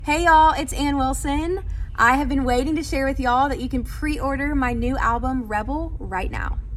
LINER Anne Wilson (preorder Rebel album)